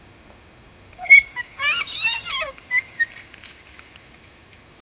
Eli's breath hitches. 0:10 Fast heartbeat 0:10 som de respirção abafada 0:10 Sudden rustle of twigs, suspenseful ambient tension, kitten’s anxious mew, distant soft echo of forest. 0:05 Dim bedroom.
sudden-rustle-of-twigs-su-5p64adpm.wav